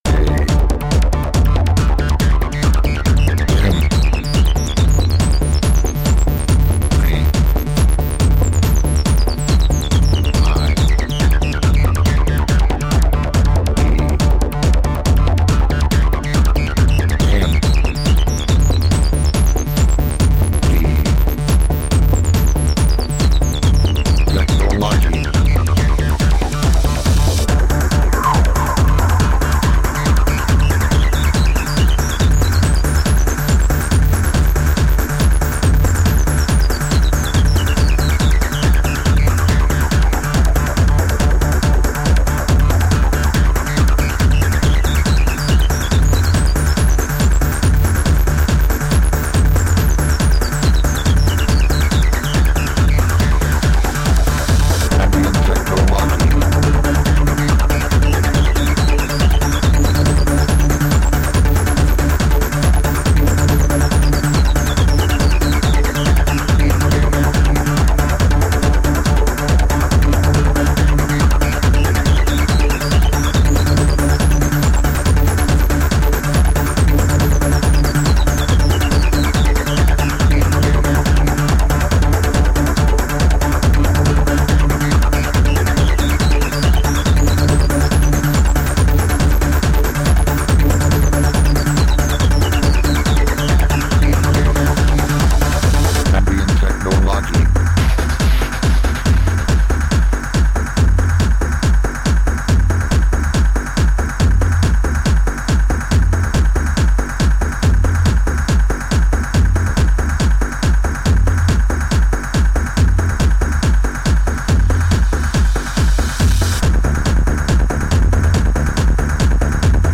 Upbeat underground with shades of new wave.
Tagged as: Electronica, Techno, IDM